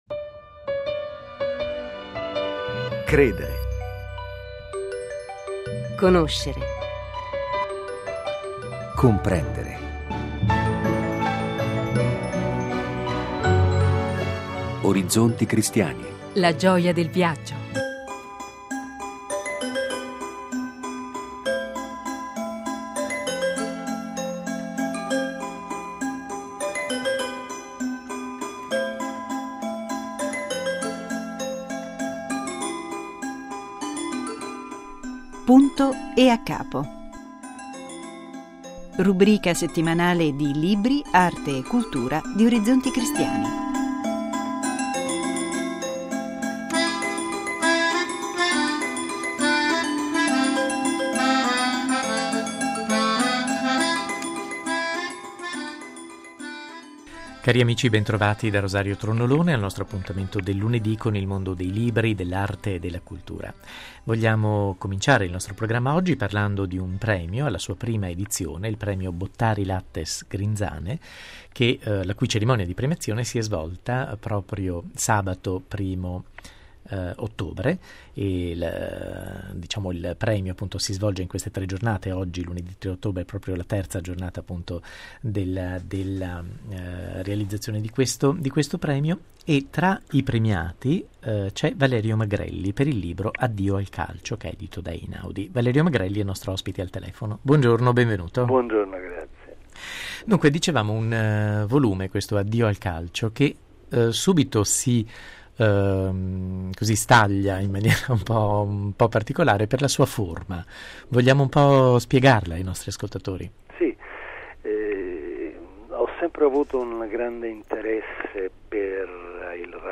lunedì 03 ottobre Dal 1° al 3 ottobre si è svolta la cerimonia di premiazione della I edizione del Premio Bottari Lattes Grinzane: due dei vincitori, Valerio Magrelli e Caterina Bonvicini, sono nostri ospiti al telefono. Per la seconda tranche del Napoli Teatro Festival Italia si è tenuta la prima nazionale della "Casa di Bernarda Alba" di Federico Garcia Lorca, per la regia di Lluis Pasqual, di cui è interprete Lina Sastri, nostra ospite al telefono.